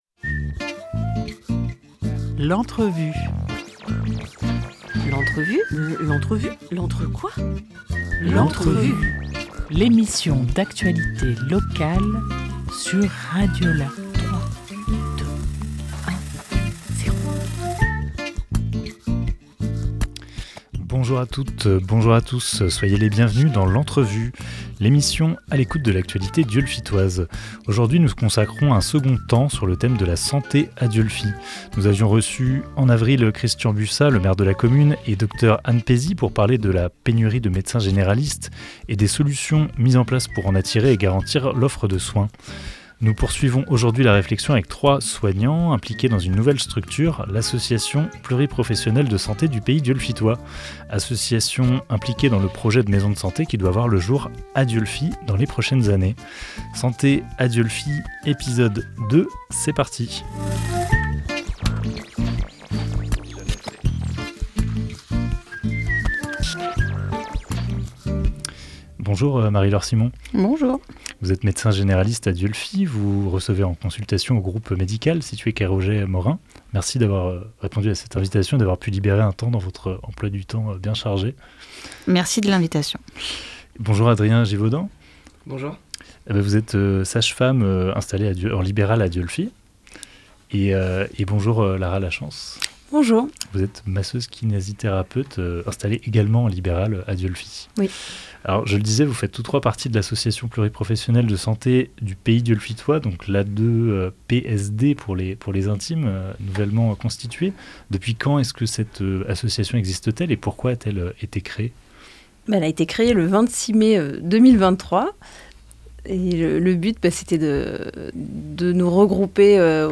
Après une première émission sur la recherche de médecins généralistes, nous poursuivons la réflexion avec trois soignants impliqués dans une nouvelle structure : l’association pluriprofessionnelle de santé du pays dieulefitois.
Interview
Sante-a-Dieulefit-2_Les-soignants-presentent-le-projet-de-maison-medicale_PAD.mp3